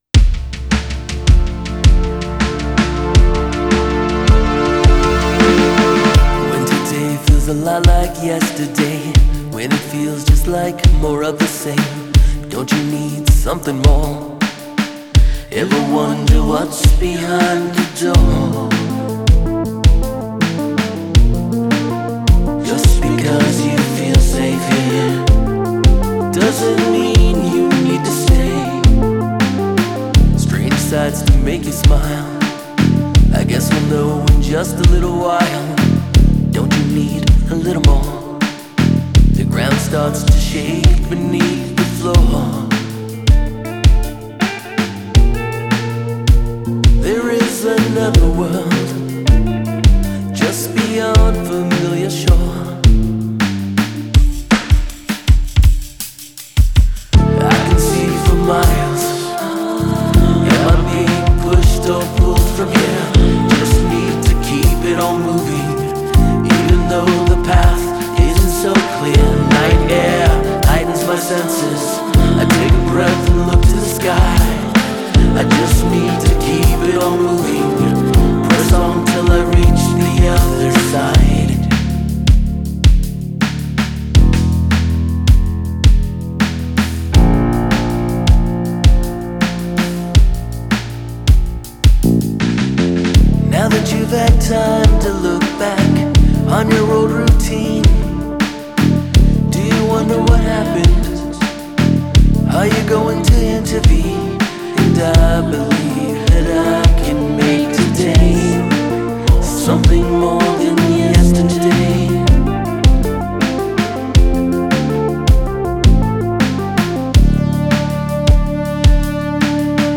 Alt Rock, Electronic